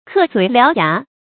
課嘴撩牙 注音： ㄎㄜˋ ㄗㄨㄟˇ ㄌㄧㄠˊ ㄧㄚˊ 讀音讀法： 意思解釋： 搬唇弄舌。